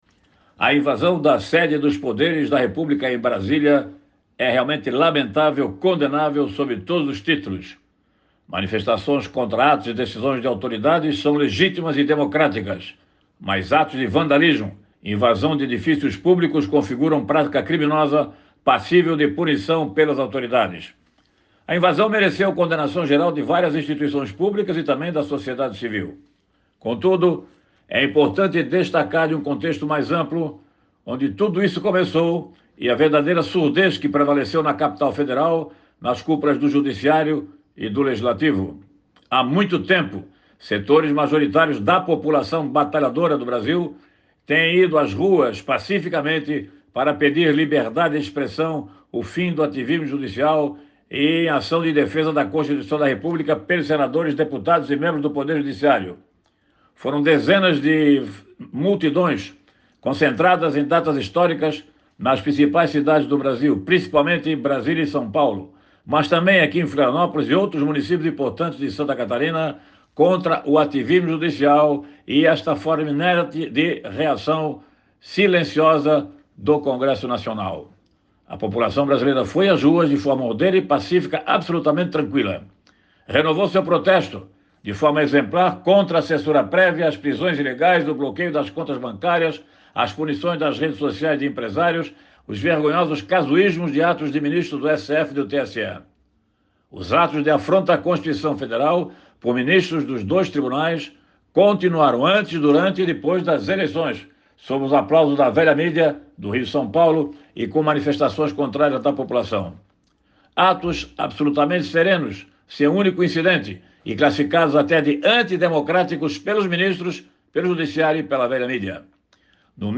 O jornalista comenta que manifestações contra atos e decisões de autoridades são legítimas e democráticas, mas atos de invasão de edifícios públicos configuram prática criminosa